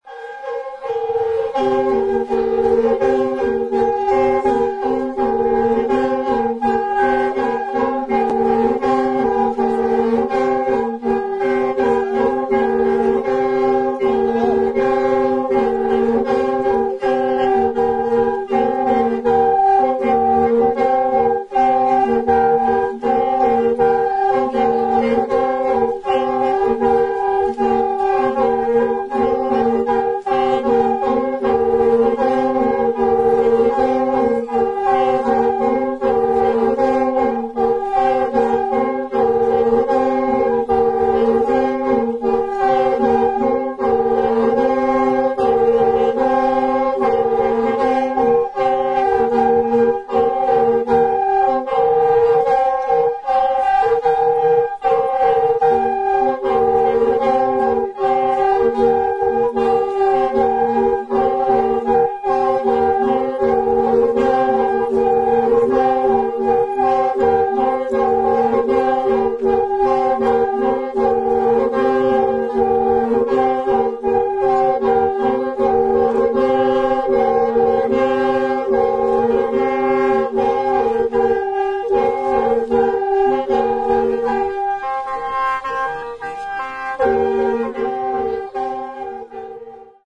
ひょうたん笛、笙、伝統的な弦楽器による音色や人々による唱法が独特の音の調和を生み出している素晴らしいフィールドレコーディング作品。